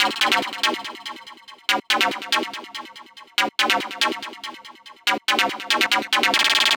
Lead 142-BPM A.wav